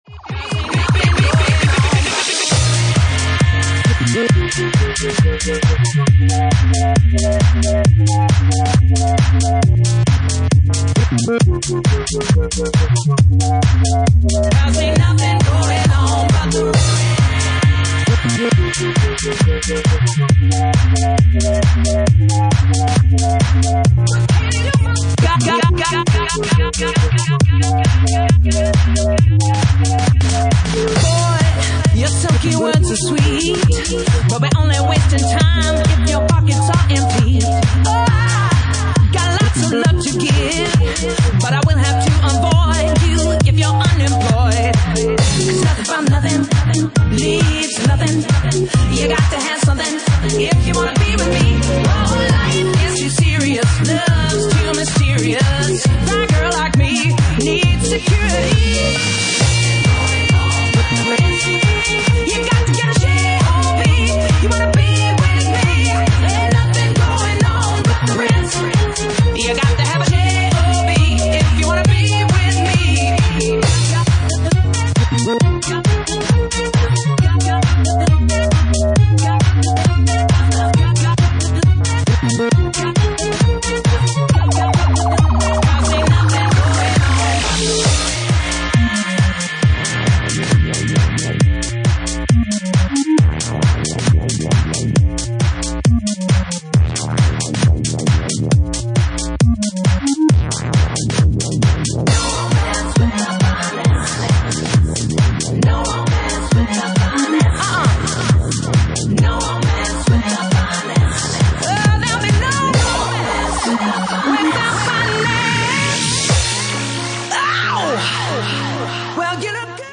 Genre:Bassline House